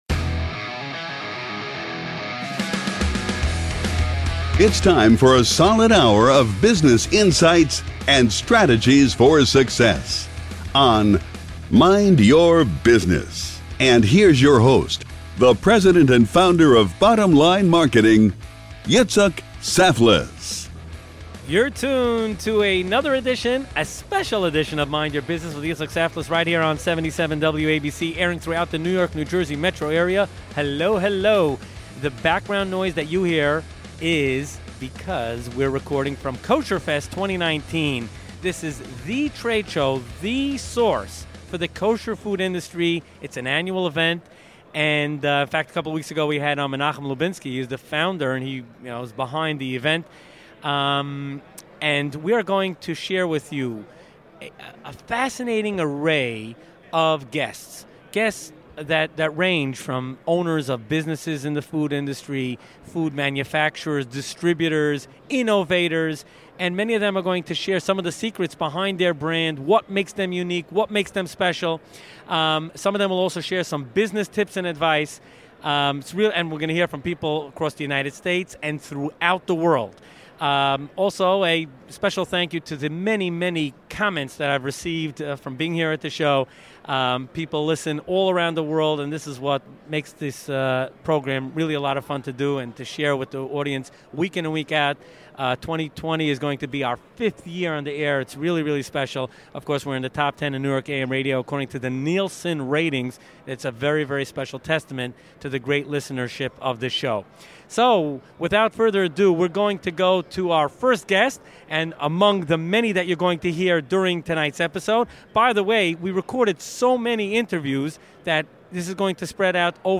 Tune in to hear this episode recorded LIVE from Kosherfest 2019, top leaders and innovators from the kosher food industry.